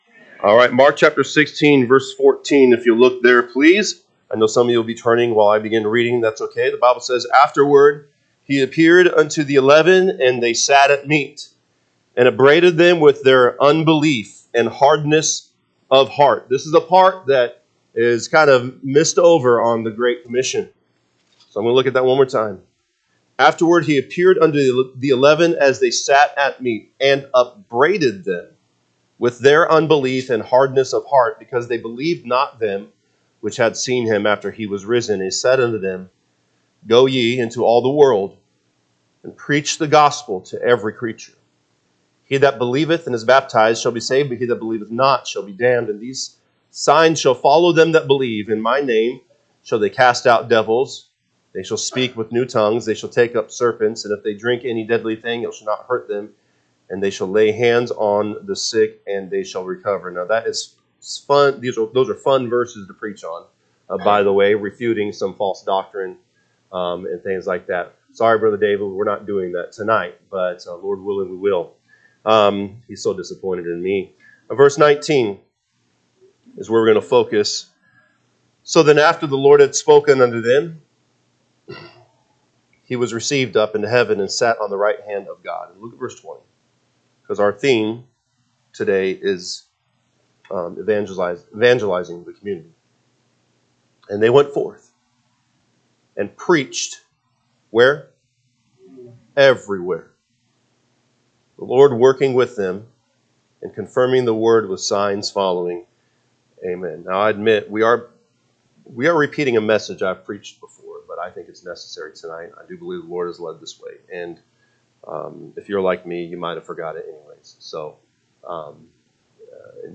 January 26, 2025 pm Service Mark 16:14-20 (KJB) 14 Afterward he appeared unto the eleven as they sat at meat, and upbraided them with their unbelief and hardness of heart, because they believe…